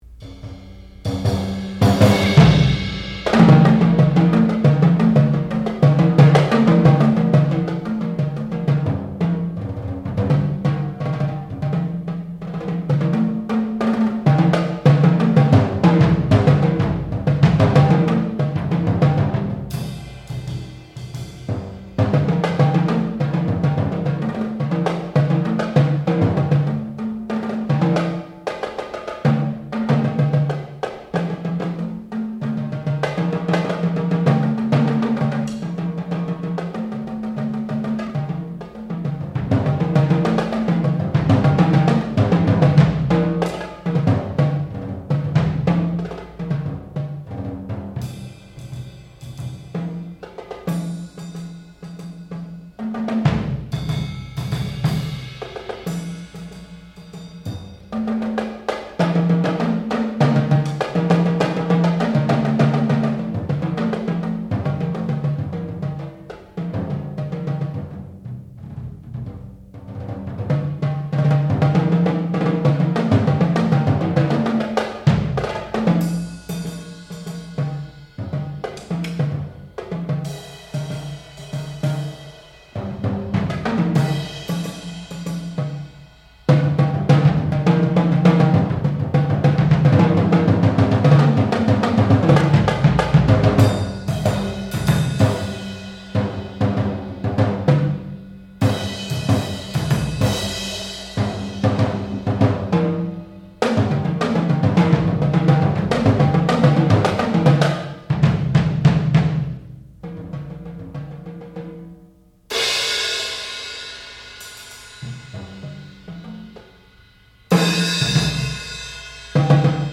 sound recording-musical
classical music
Master's Recital
percussion